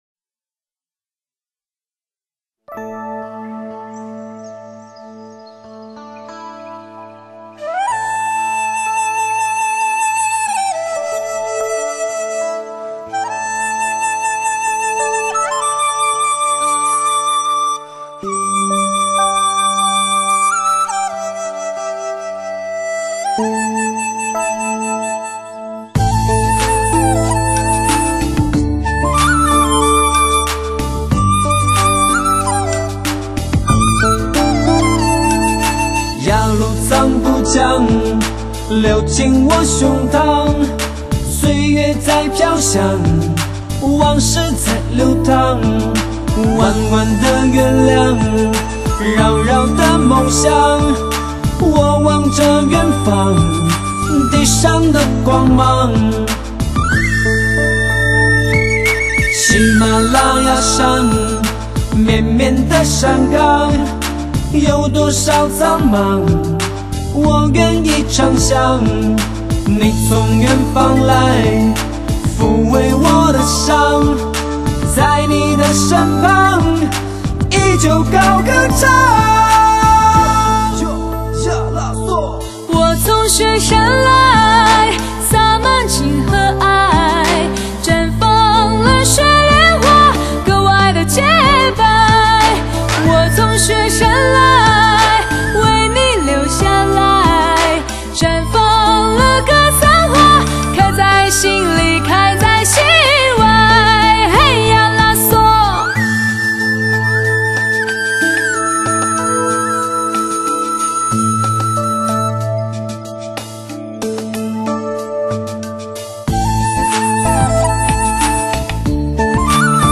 透过人声连接心与心的距离
为人们呈现最真实的原音 表现最诚挚的感情